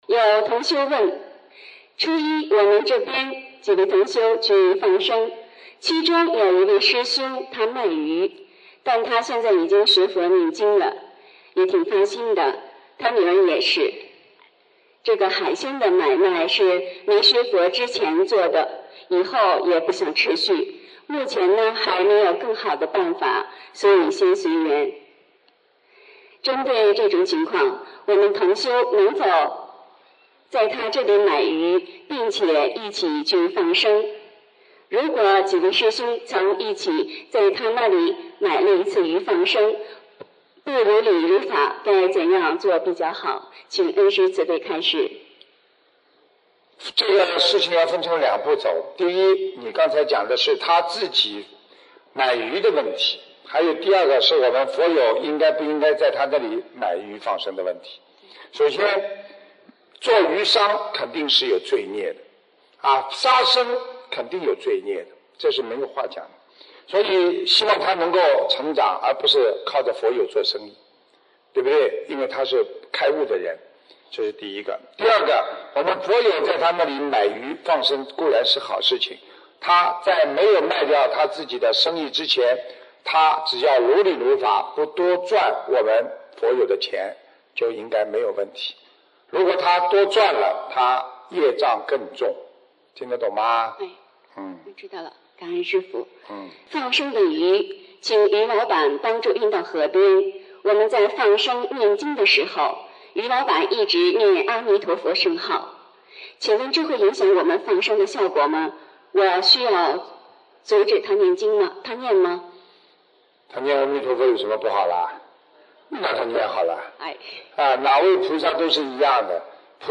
首页 >>博客精选 >> 2016年弟子提问师父答